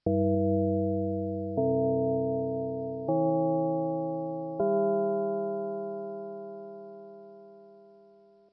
bell_long.mp3